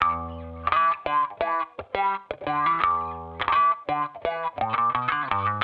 Index of /musicradar/sampled-funk-soul-samples/85bpm/Guitar
SSF_StratGuitarProc2_85E.wav